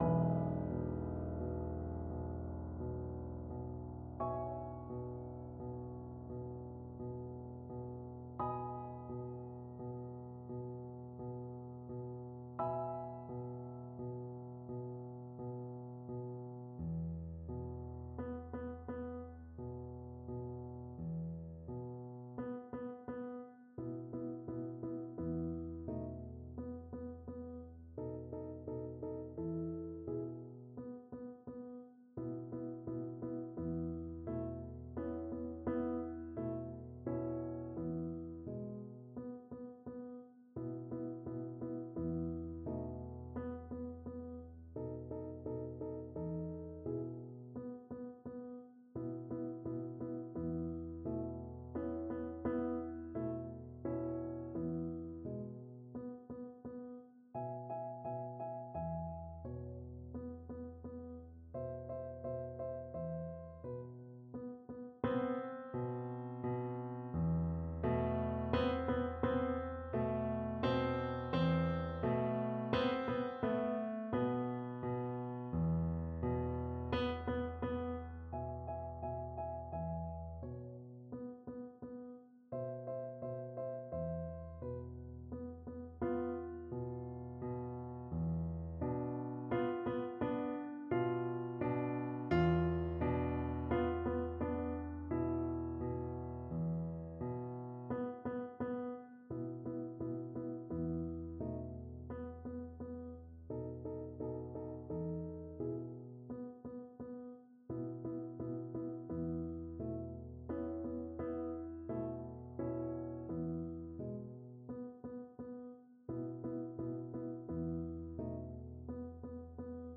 Play (or use space bar on your keyboard) Pause Music Playalong - Piano Accompaniment Playalong Band Accompaniment not yet available transpose reset tempo print settings full screen
Violin
Lent et mystrieux (trs calme) . = 44
12/8 (View more 12/8 Music)
E major (Sounding Pitch) (View more E major Music for Violin )
Classical (View more Classical Violin Music)